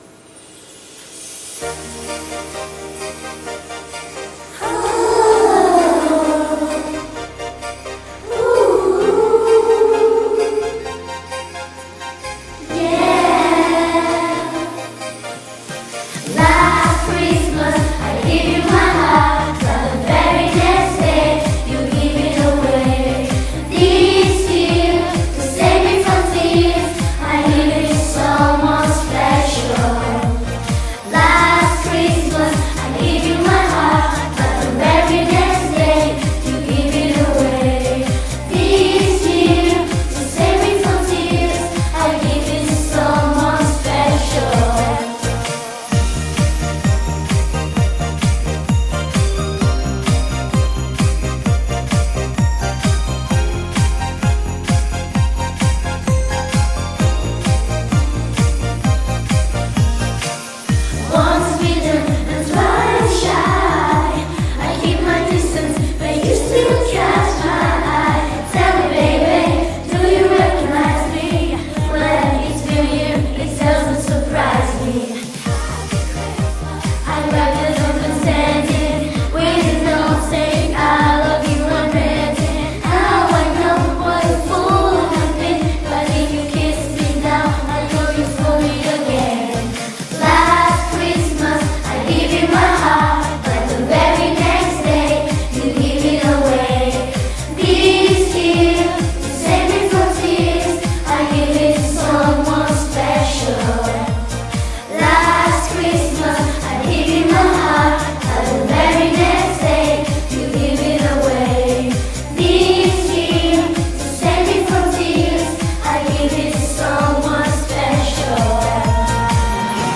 Els alumnes de 4t i 5è, van tenir molt feina amb la cançó “LAST CHRISTMAS” perquè es van haver d’aprendre tota la cançó